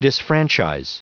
Prononciation du mot disfranchise en anglais (fichier audio)
Prononciation du mot : disfranchise